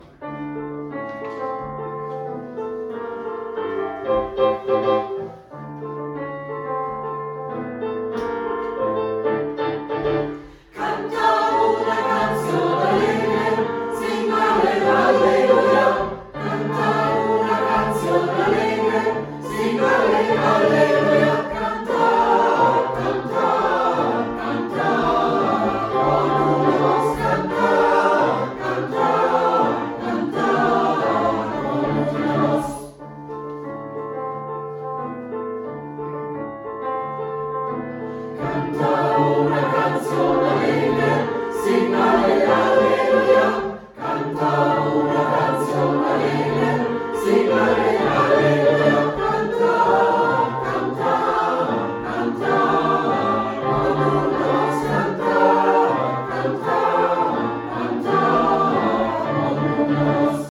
Herzlich Willkommen beim Gospelchor
Die Lieder wurden während unserer Probe mit einem Handy aufgenommen und haben keine Studioqualität.